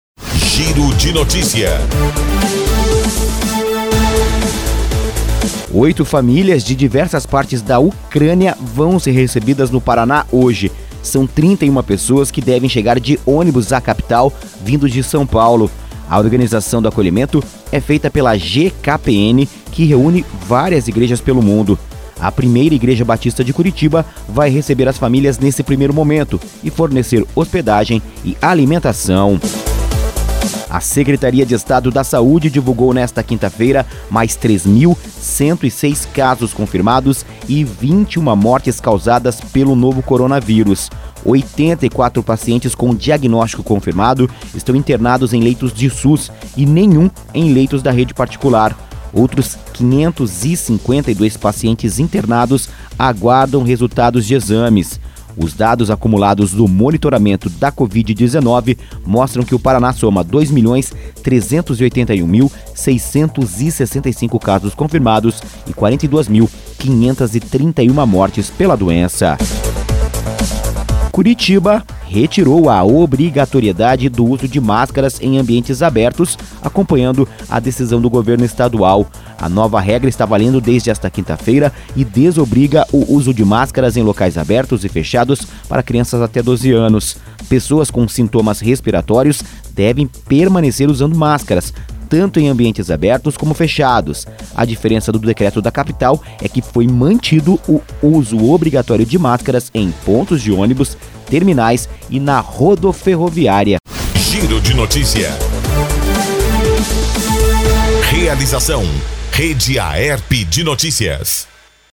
Associação das Emissoras de Radiodifusão do Paraná